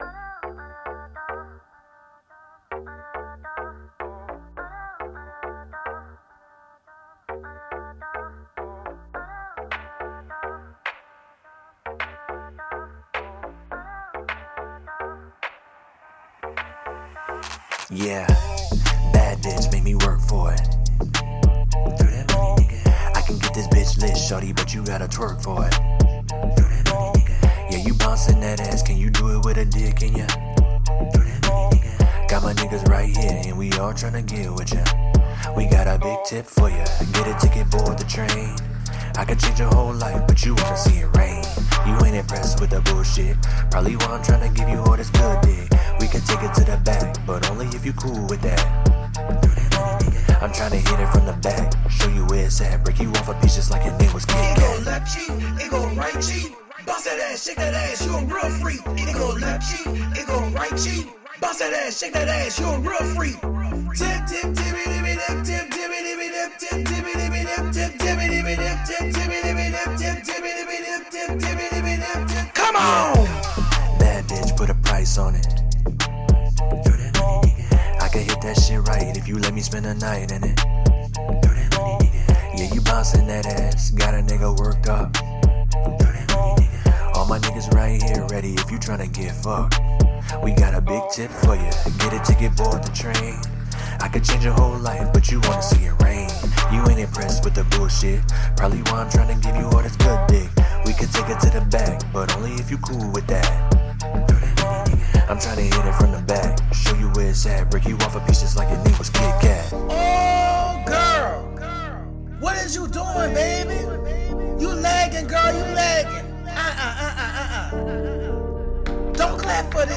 Created 2024-01-23 20:58:03 Hip hop 0 ratings